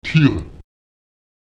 Lautsprecher tir [tir] obwohl